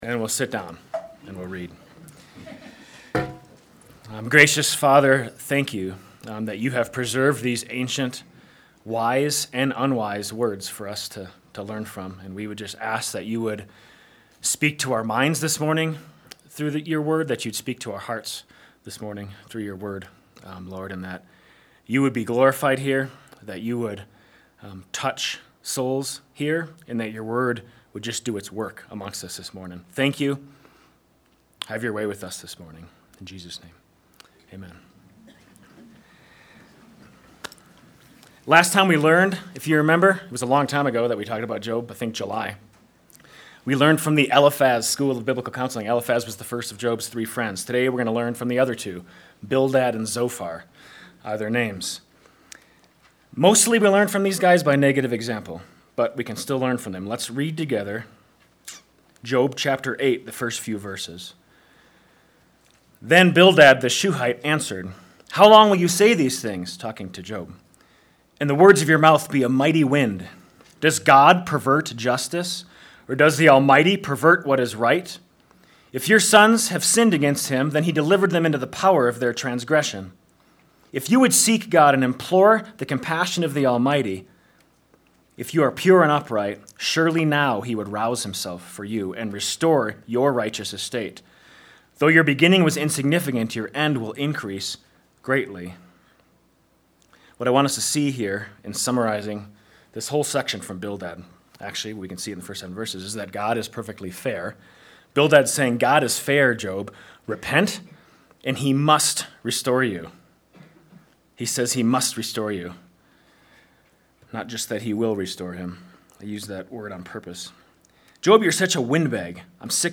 Sermons | Mountain Christian Church